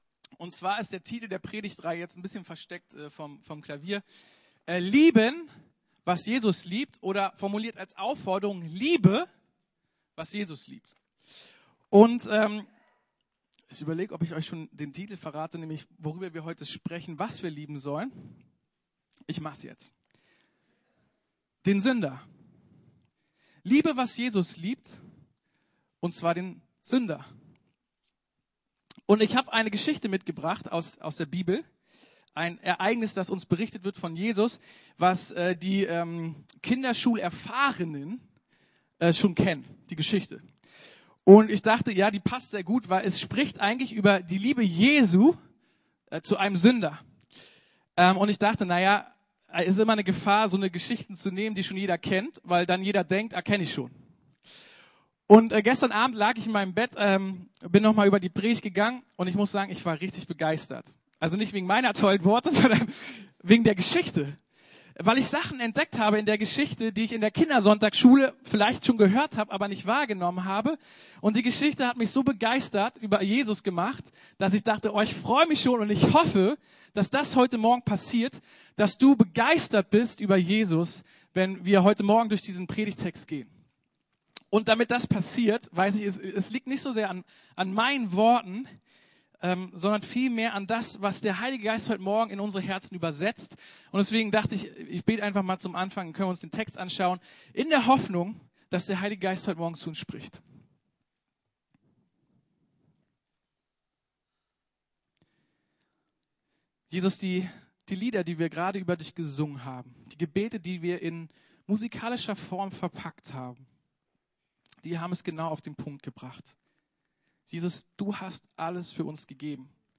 Lieben, was Jesus liebt: Den Sünder ~ Predigten der LUKAS GEMEINDE Podcast